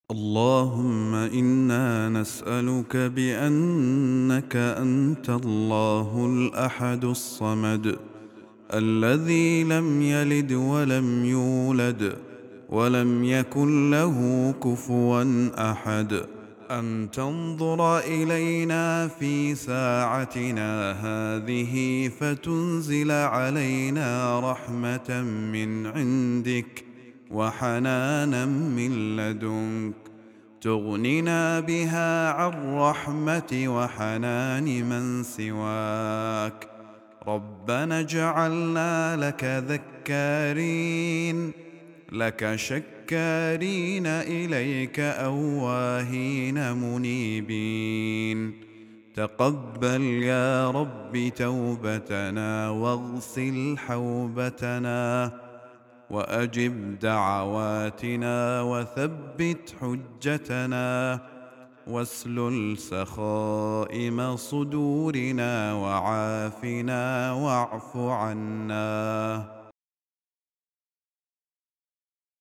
دعاء خاشع ومناجاة مؤثرة تتوسل إلى الله تعالى باسمائه وصفاته، طلبًا للرحمة والمغفرة والعفو. يدعو الداعي ربه أن يتقبل توبته ويستجيب دعاءه ويثبته على الحق، مع التركيز على صلاح القلب والأنس بالله.